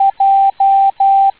Datei:J morse code.ogg
Beschreibung Morse Code Character J